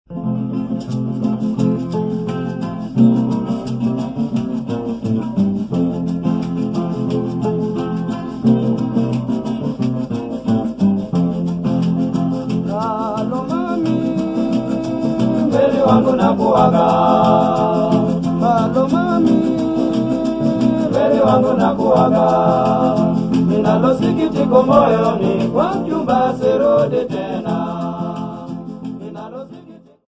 Folk music--Africa
Field recordings
Africa, Sub-Saharan
sound recording-musical
Love song accompanied by three guitars and rattle.